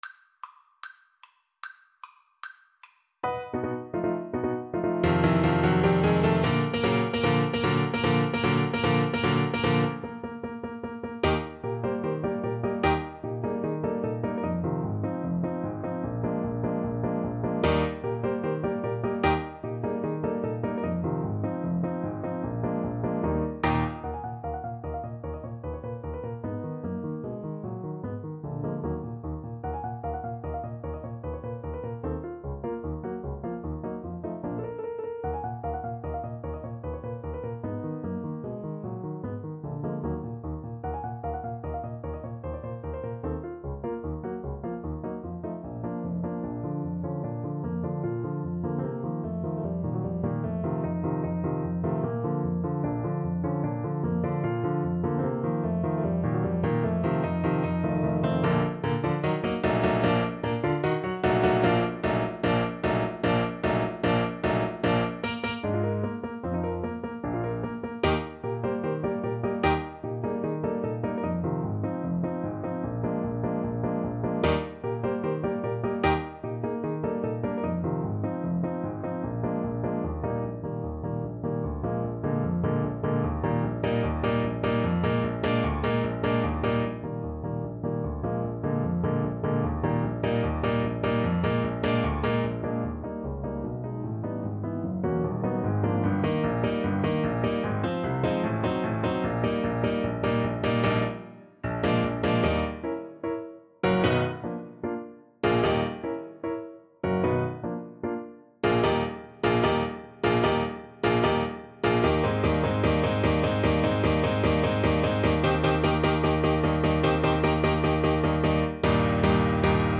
Play (or use space bar on your keyboard) Pause Music Playalong - Piano Accompaniment Playalong Band Accompaniment not yet available transpose reset tempo print settings full screen
Eb major (Sounding Pitch) C major (Alto Saxophone in Eb) (View more Eb major Music for Saxophone )
2/4 (View more 2/4 Music)
Allegro vivacissimo ~ = 150 (View more music marked Allegro)
Classical (View more Classical Saxophone Music)